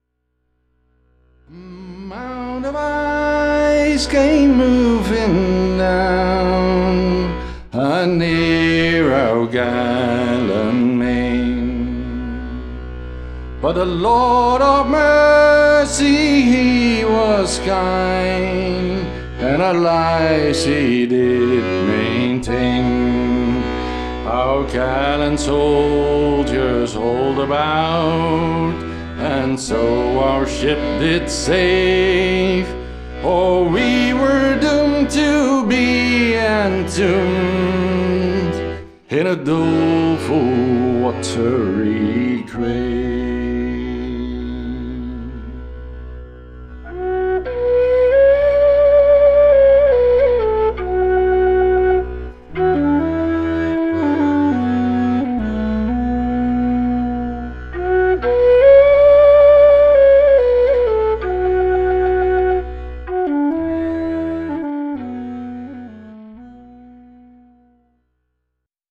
traditional irish and scottish music